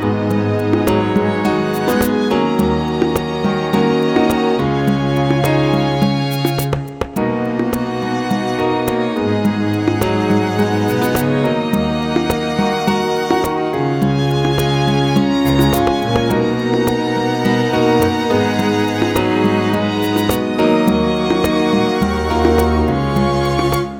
Multiplex Lead Version